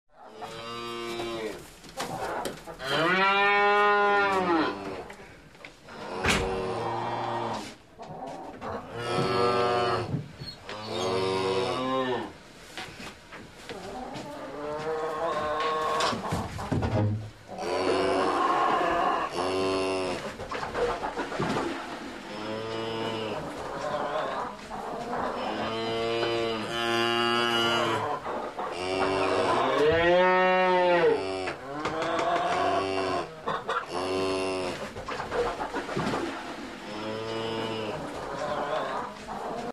Звуки скотного двора
Звуки коров в сарае ночью